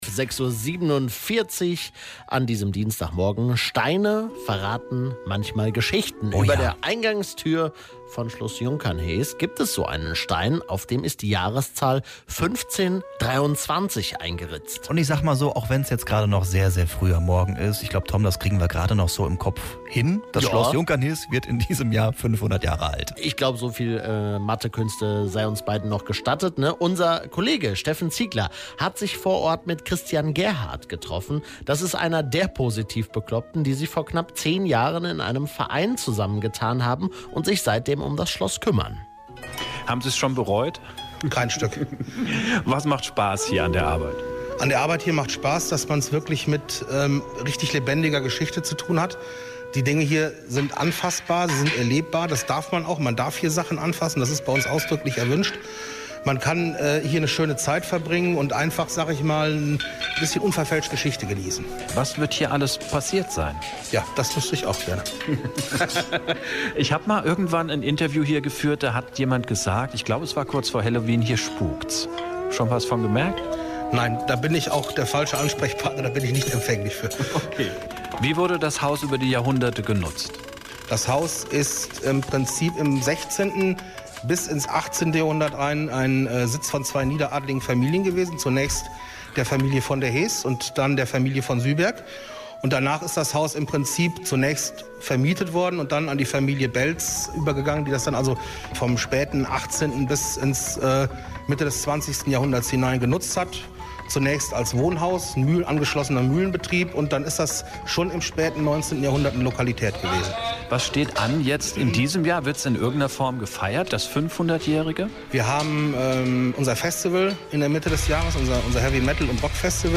mitschnitt-junkernhees-interview.mp3